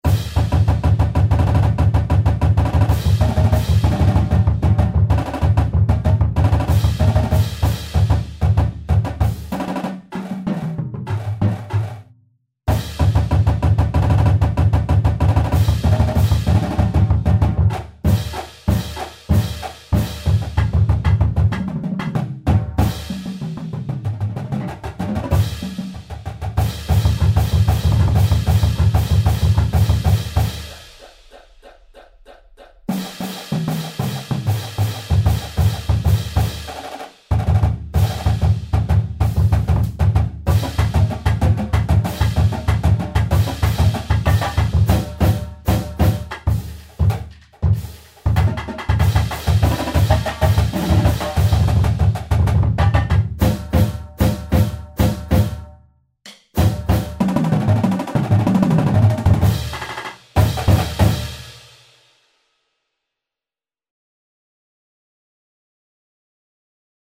Snare Quads (5 Drums) 4 Bass Drums 1 Cymbal